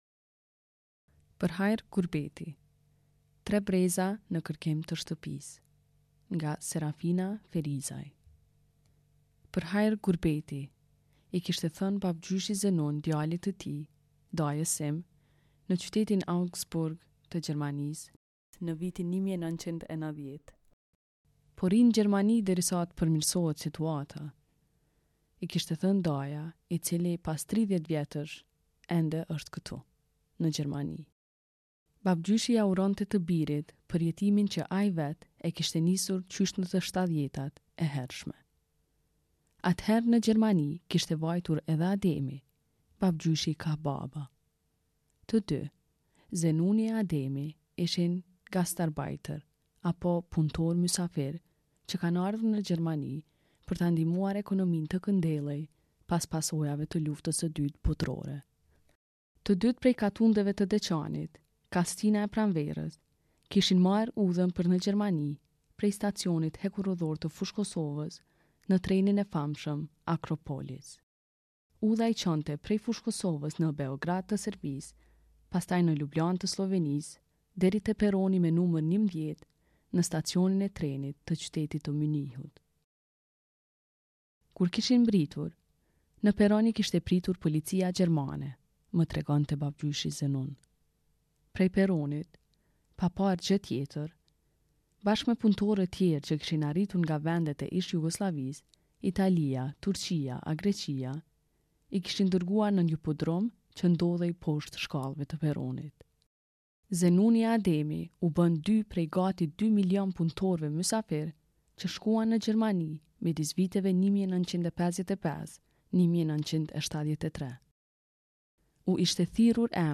Më poshtë gjeni një version të lexuar me zë të të gjithë artikullit.